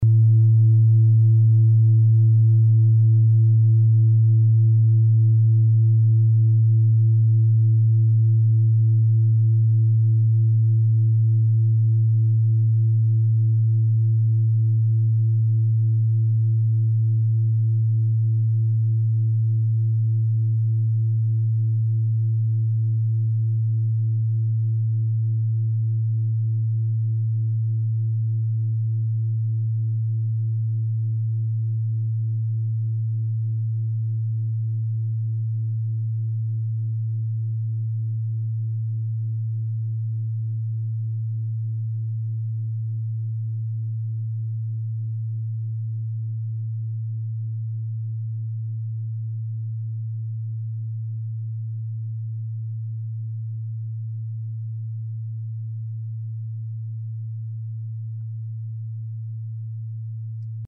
Klangschale TIBET Nr.36
Sie ist neu und ist gezielt nach altem 7-Metalle-Rezept in Handarbeit gezogen und gehämmert worden..
Diese Frequenz kann bei 224Hz hörbar gemacht werden, das ist in unserer Tonleiter nahe beim "A".
klangschale-tibet-36.mp3